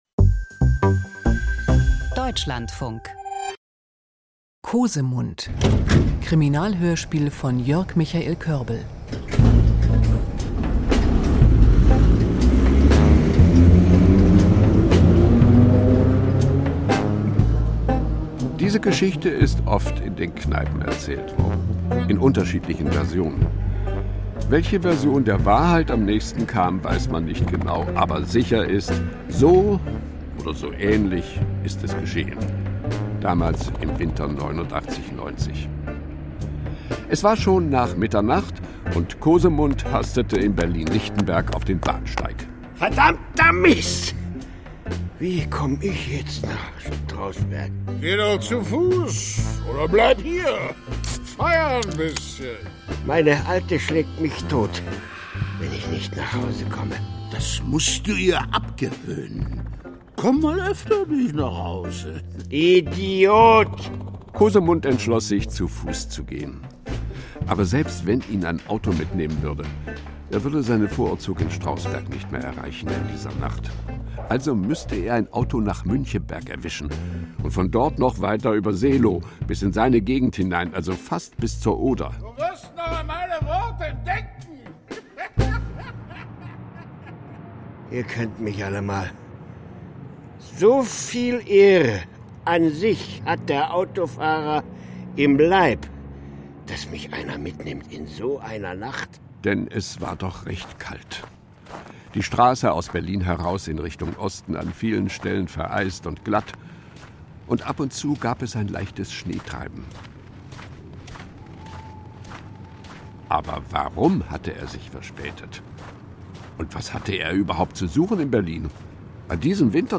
Krimi-Komödie nach dem Mauerfall